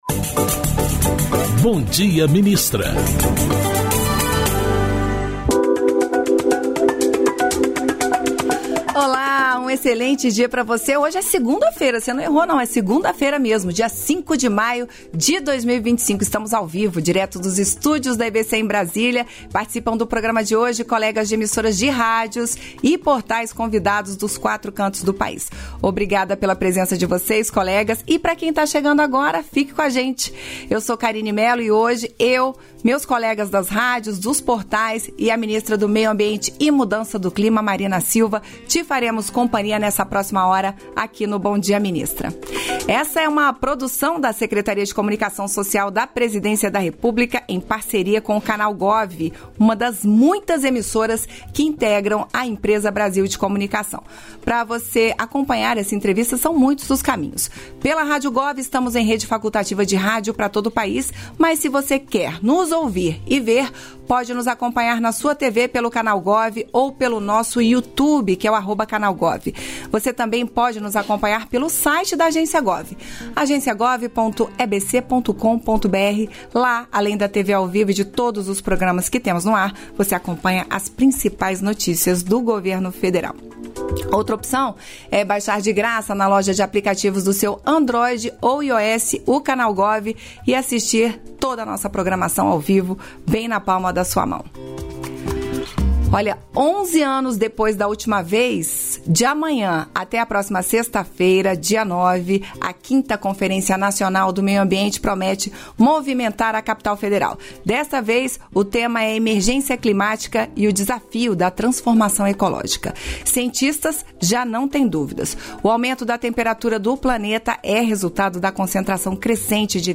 Íntegra da participação ministra do Meio Ambiente e Mudança do Clima, Marina Silva, no programa "Bom Dia, Ministra" desta segunda-feira (5), nos estúdios da EBC em Brasília (DF).